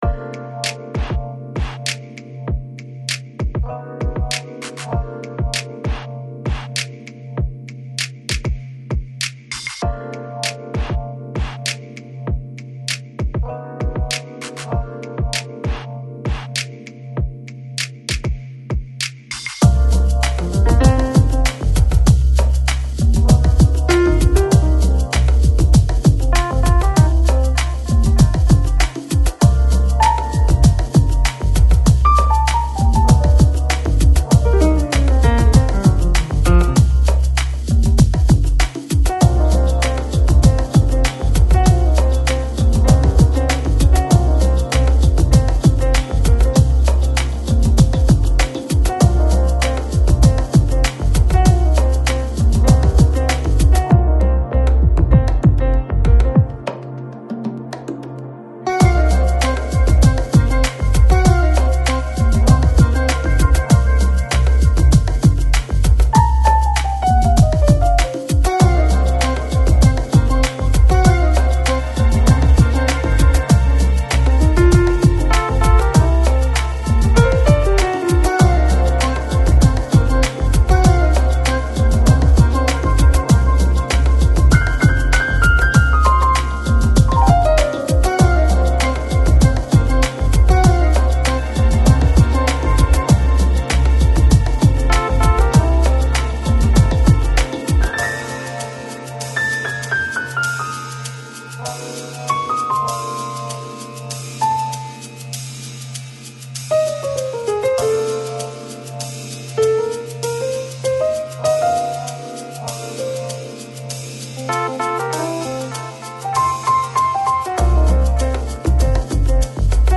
Жанр: Electronic, Lounge, Downtempo, Chill Out, Balearic